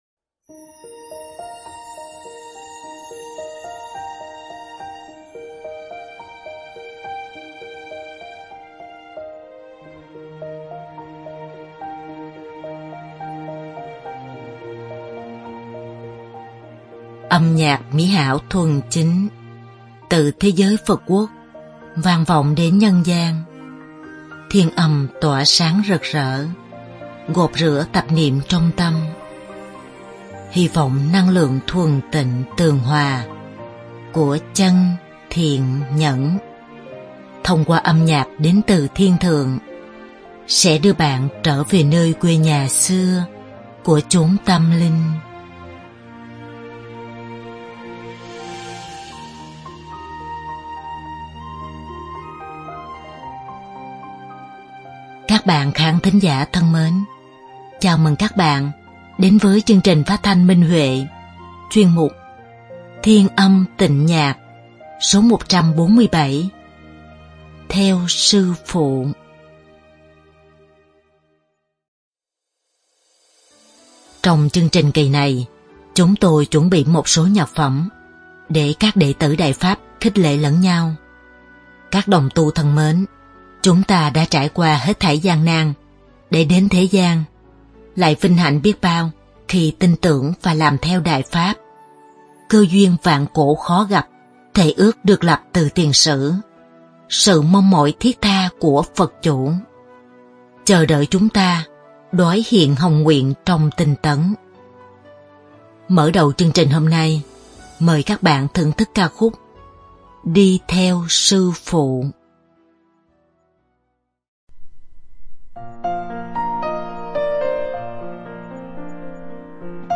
Đơn ca nữ
Nhạc phẩm